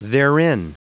Prononciation du mot therein en anglais (fichier audio)
Prononciation du mot : therein